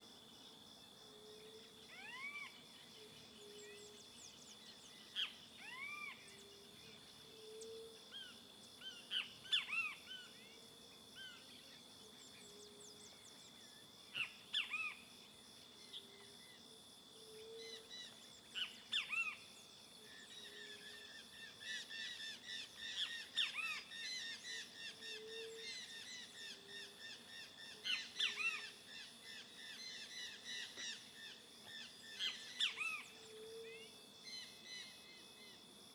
Bem te vi e outros passaros, grilos, cigarras e moscas no vale Bem-te-vi , Cigarra , Grilo , Insetos , Juriti , Mosca , Pássaros , Rural , Vale Goiás Velho Stereo
CSC-07-083-LE - Bem te vi e outros passaros, grilos, cigarras e moscas no vale.wav